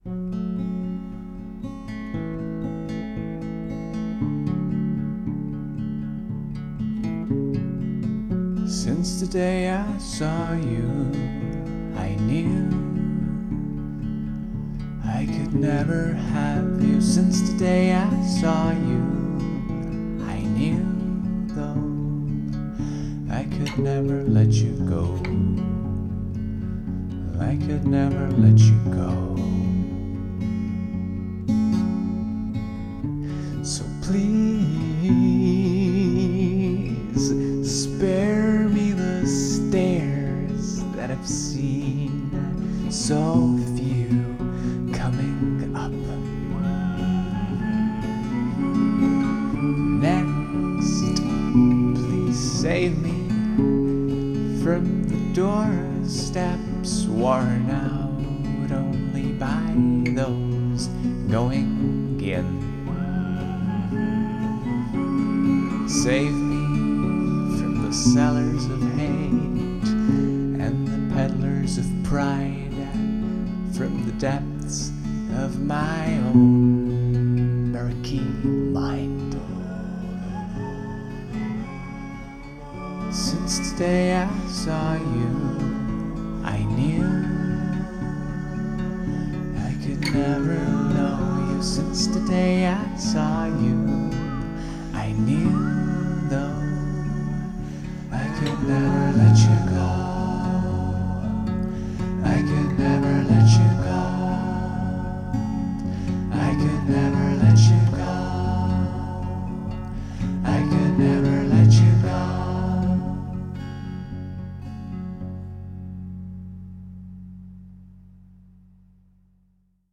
Pole ammu laulnud.